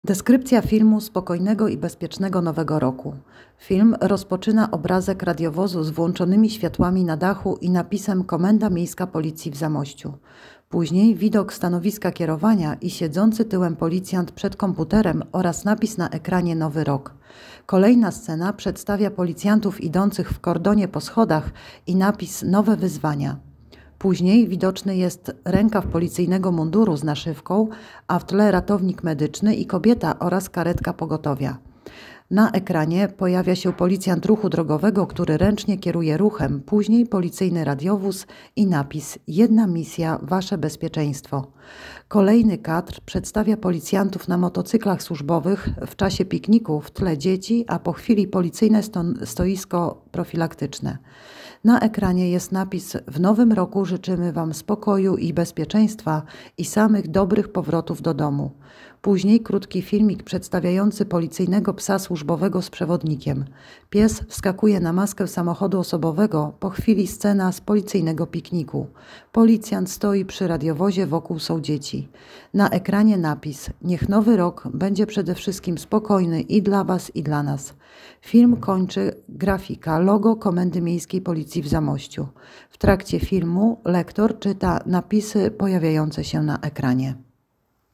Nagranie audio Audiodekrypcja filmu Spokojnego i bezpiecznego Nowego Roku